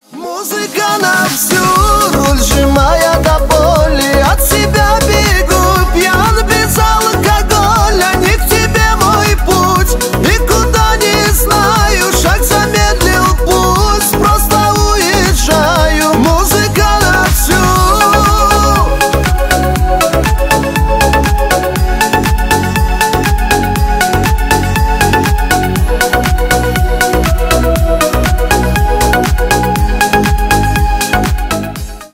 • Качество: 320, Stereo
поп
мужской голос
русский шансон
кавказские